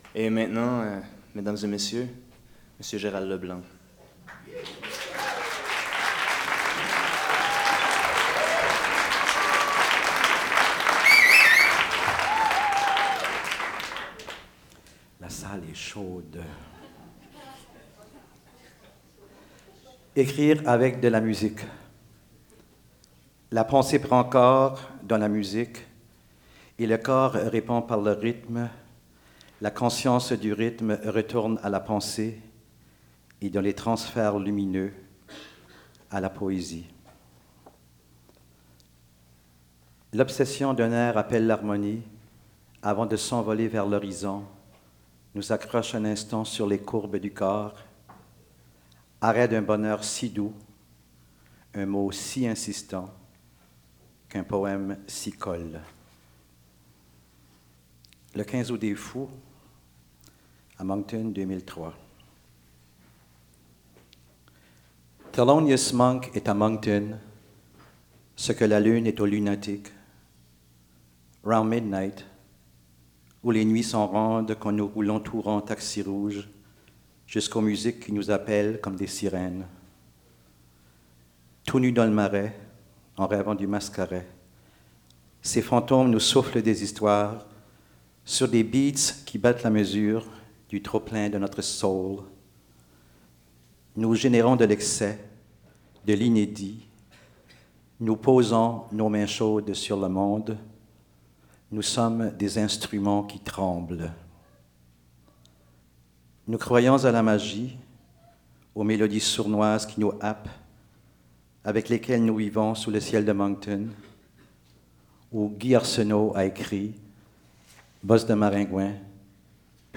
Lecture par Gérald Leblanc lors du spectacle «Étoiles filantes» présenté le  samedi 8 mai 2004 au Lion d’Or dans le cadre du 10e Festival international de la littérature (FIL), et diffusé en différé par la suite à l’émission «Les Décrocheurs d’étoiles» sur les ondes de la Chaîne culturelle de Radio-Canada.